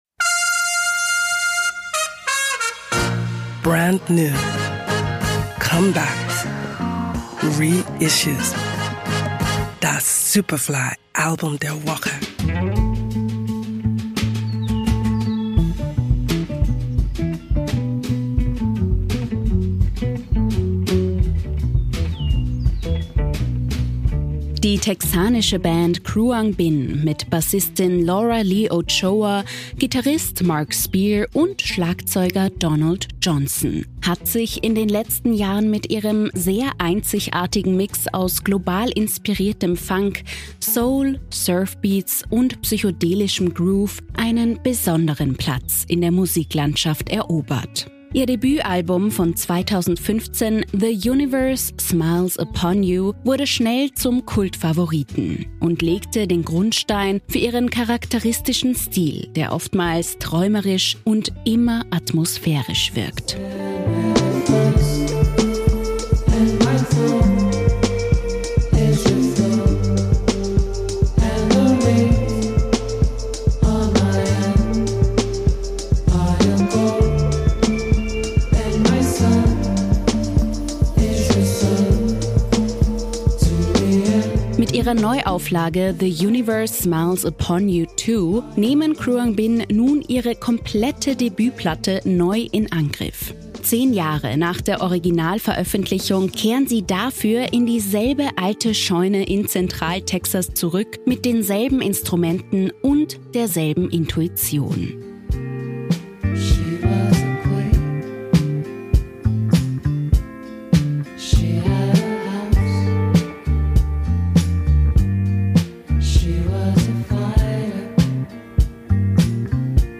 der oftmals träumerisch und immer atmosphärisch wirkt.